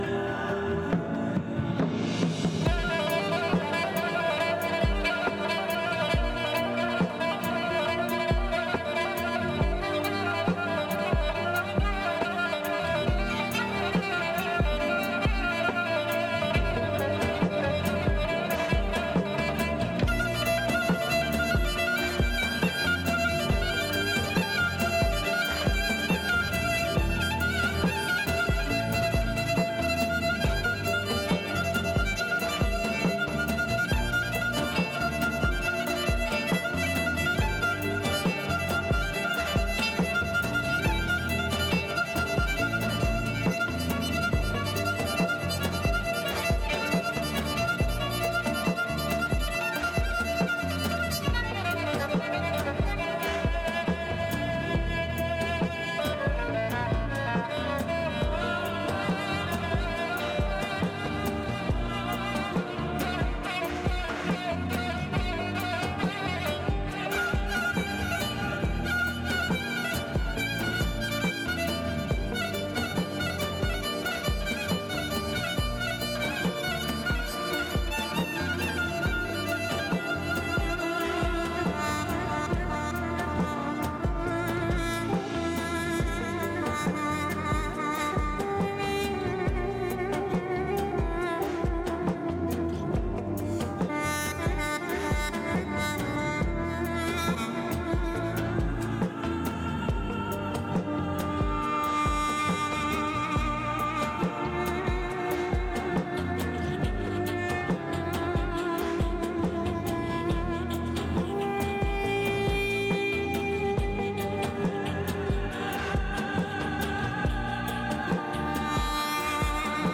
موسیقی بی کلام پایانی